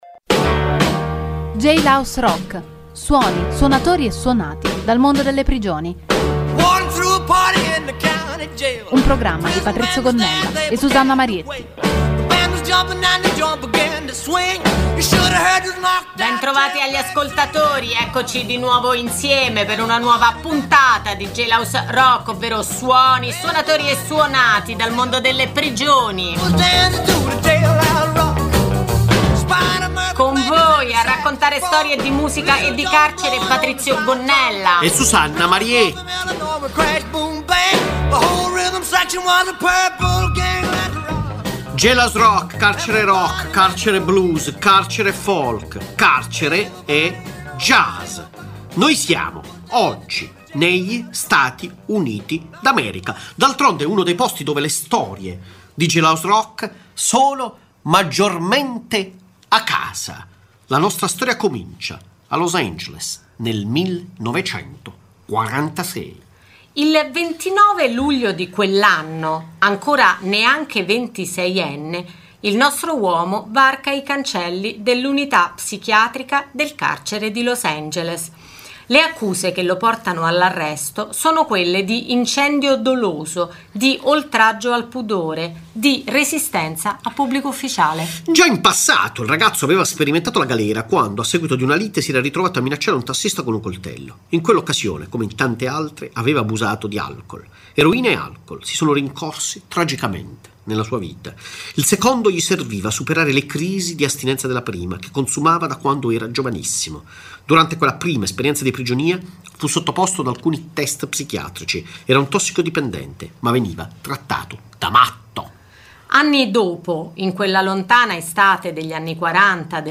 "Jailhouse Rock", trasmissione di Radio Popolare e Popolare Network, esplora il legame tra musica e carcere.
il programma include storie e suoni dal mondo delle prigioni, con la partecipazione di detenuti dei carceri di Rebibbia e Bollate che realizzano un Giornale Radio dal Carcere e cover di artisti.